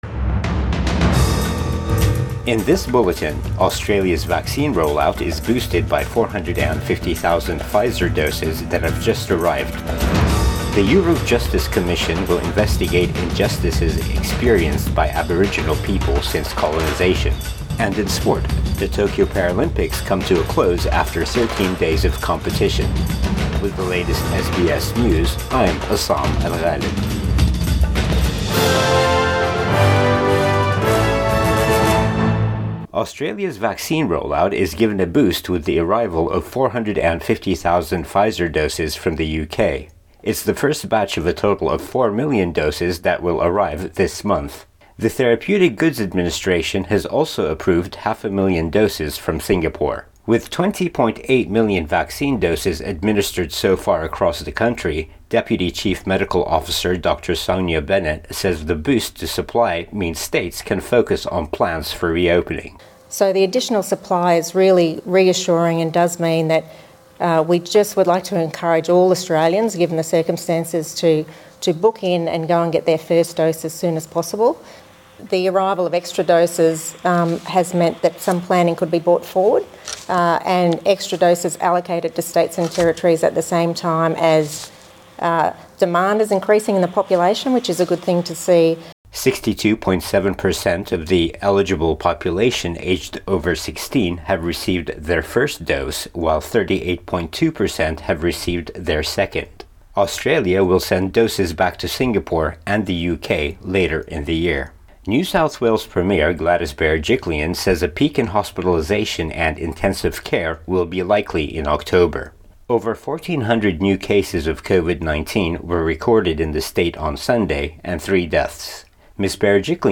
AM bulletin 6 September 2021